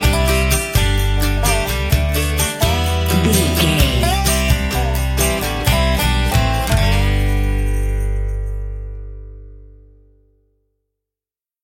Ionian/Major
acoustic guitar
bass guitar
banjo